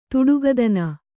♪ tuḍuga dana